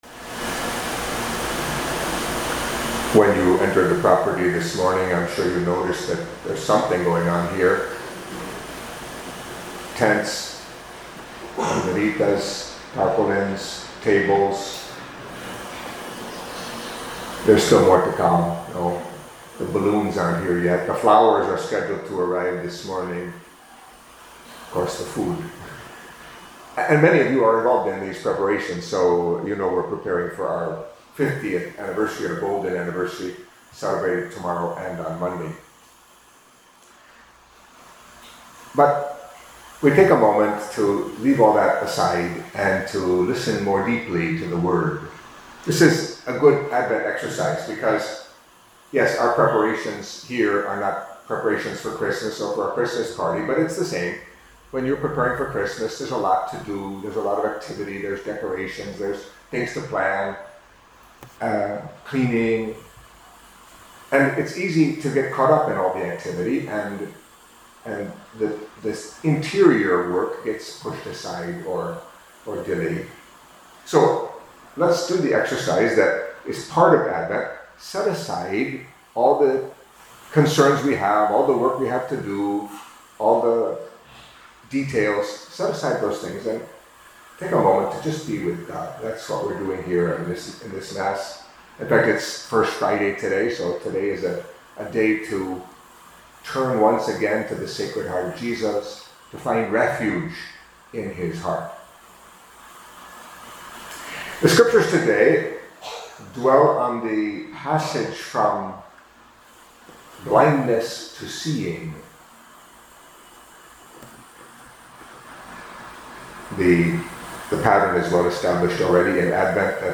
Catholic Mass homily for Friday of the First Week of Advent